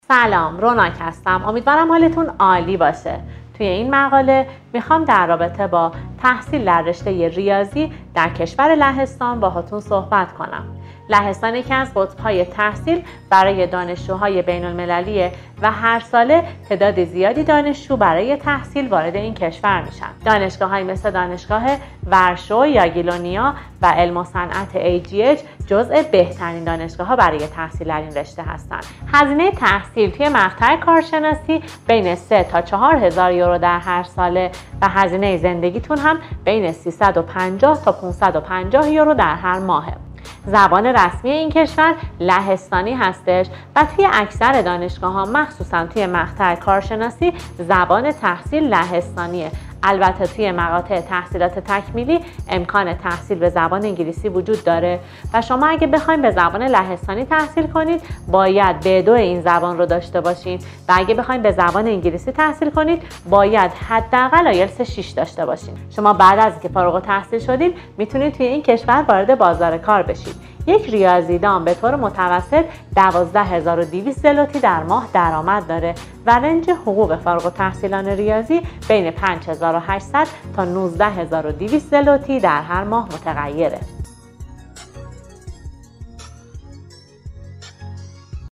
همکار ما